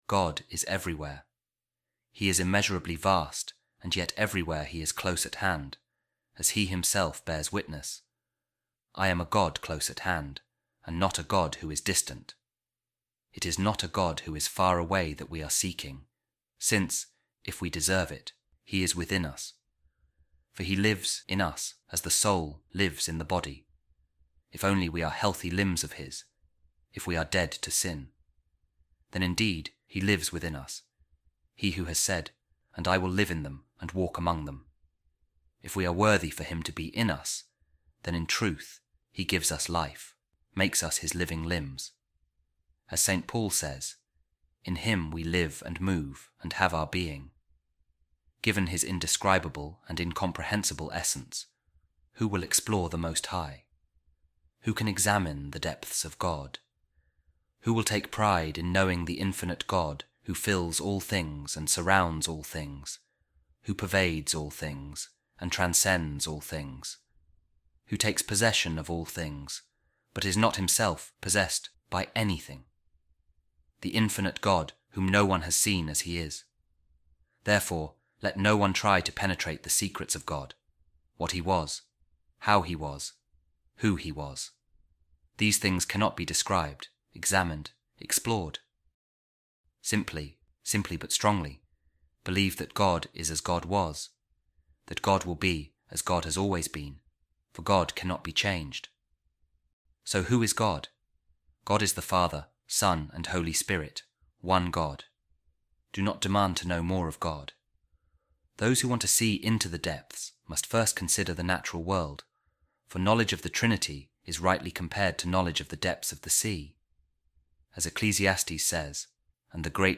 A Reading From The Instruction Of Saint Columbanus